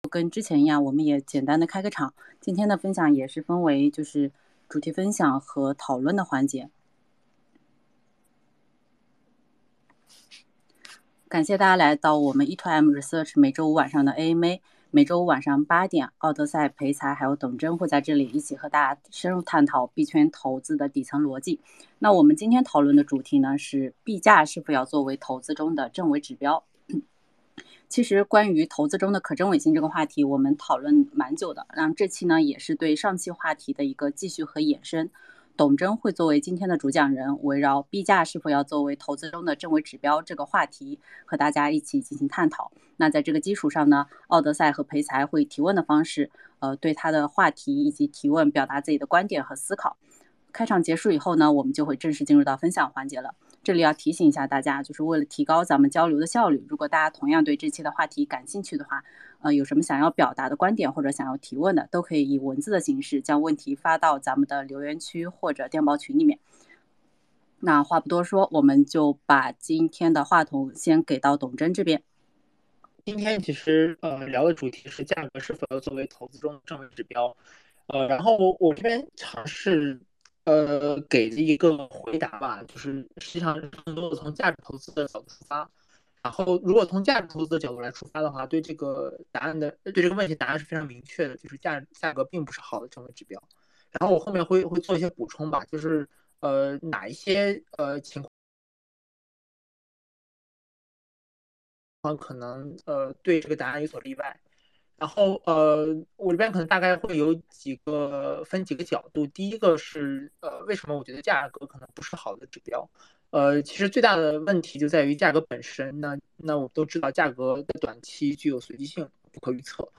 前言概述 本周 E2M research 三位主讲人一起探讨《价格是否要作为投资中的证伪指标？》 &nbsp